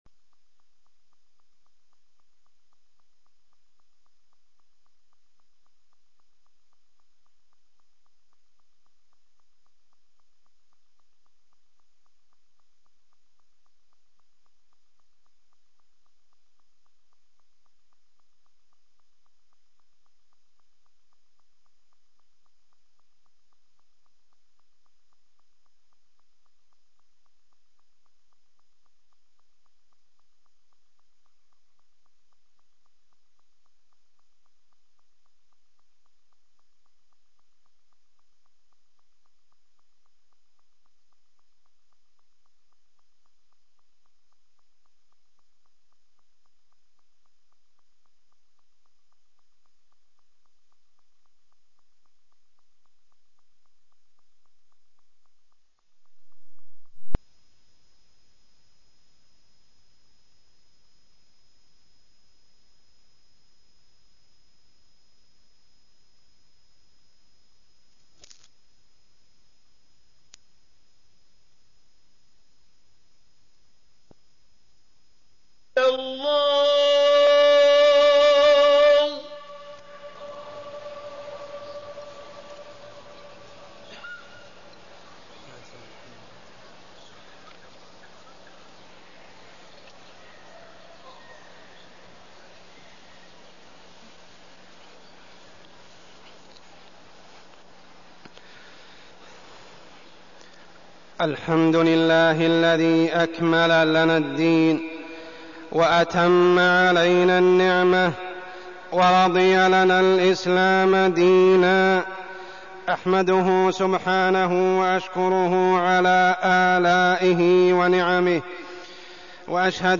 تاريخ النشر ٢٥ رجب ١٤١٧ هـ المكان: المسجد الحرام الشيخ: عمر السبيل عمر السبيل معجزة الإسراء والمعراج